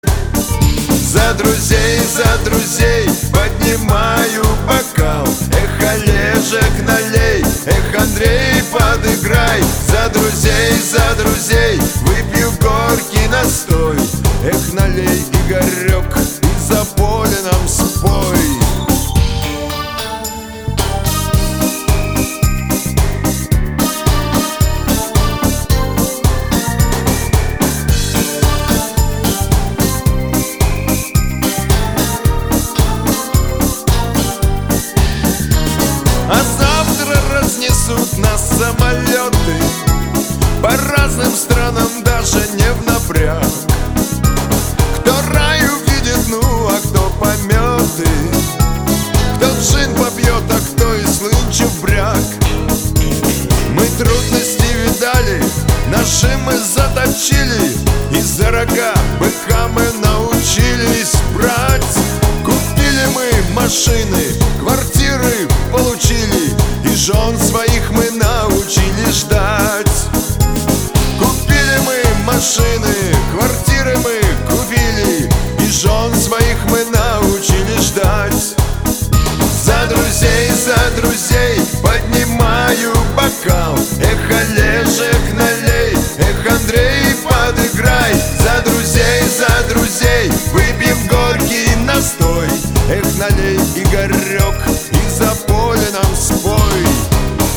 Нарезки шансона